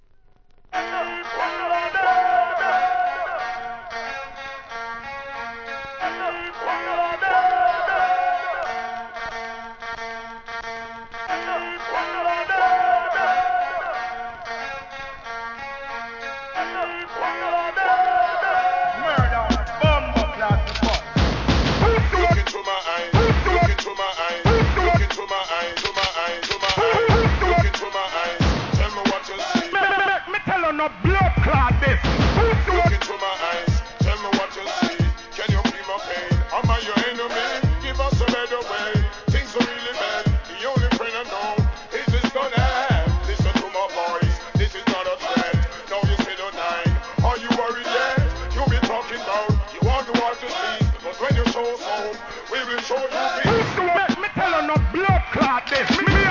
HIP HOP X REGGAEのUNRELEASED REMIXES!!